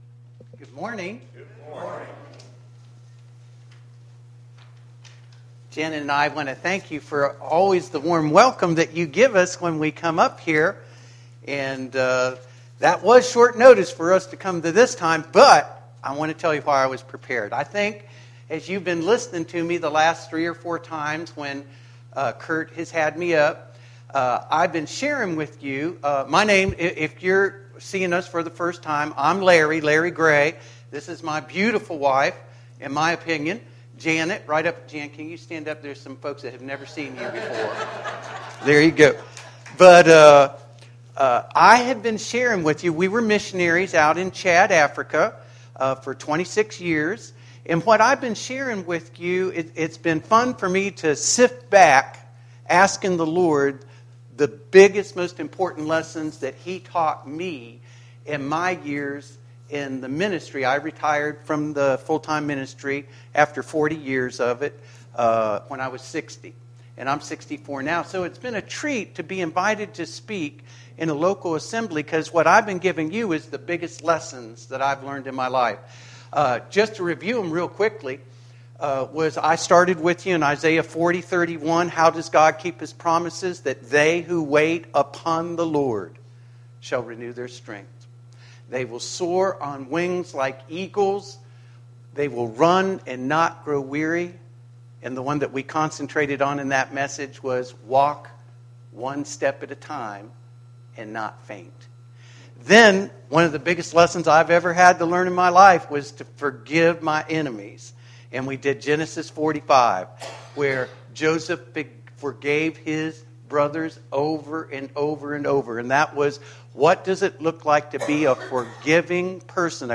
Sermons - 2016